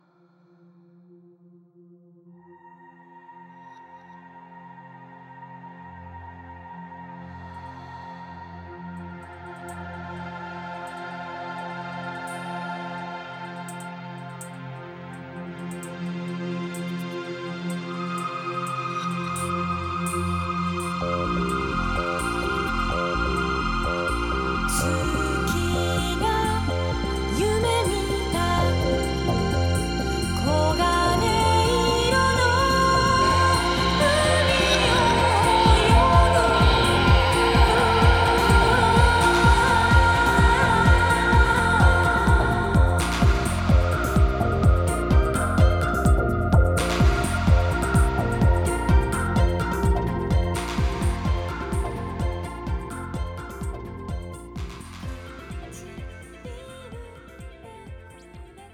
Disco House Wave Pop